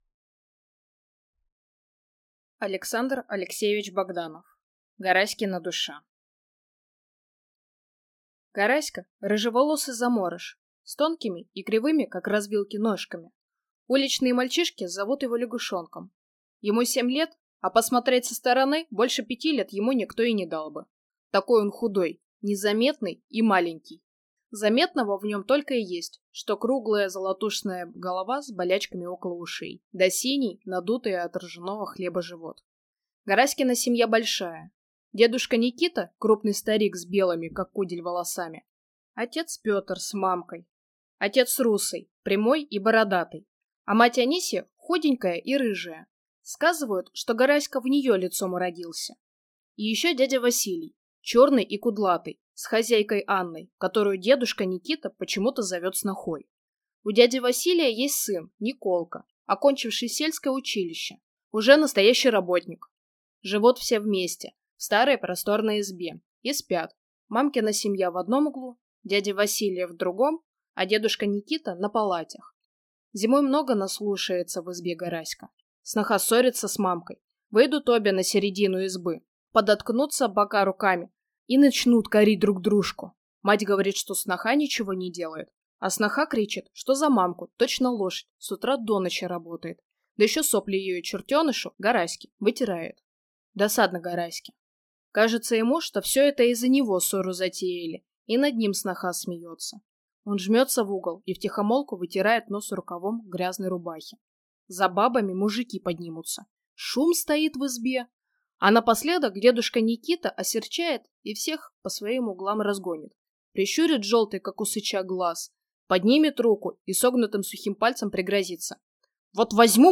Аудиокнига Гараськина душа | Библиотека аудиокниг